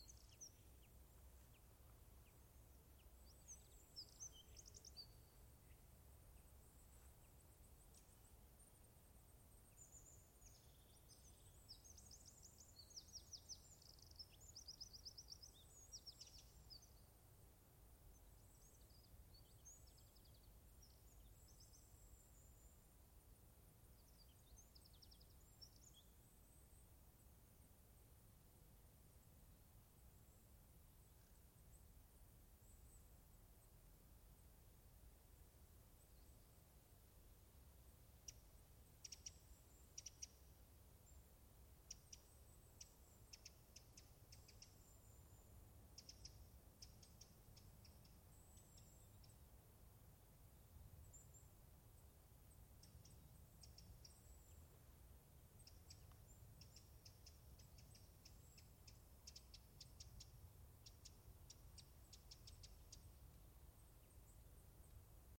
Звуки летнего луга: Раннее утро среди трав